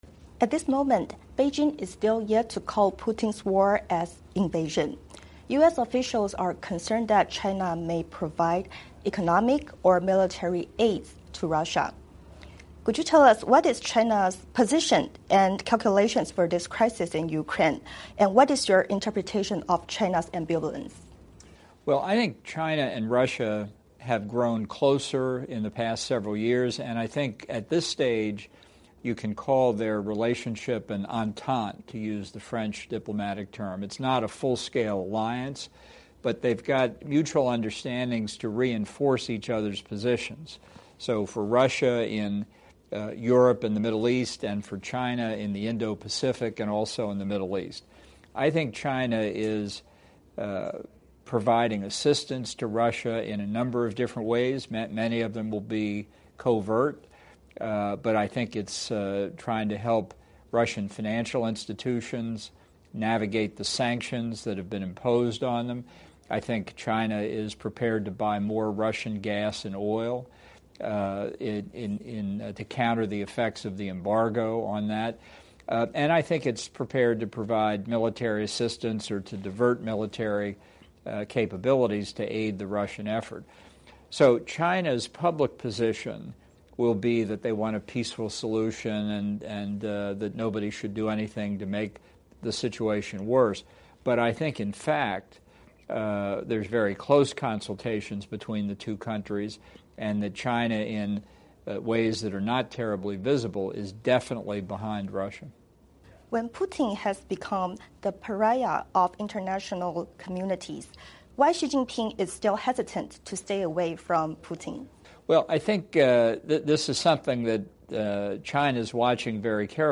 VOA专访: 专访前国安顾问博尔顿：中国在幕后援助俄罗斯 观察西方态度以算计攻台代价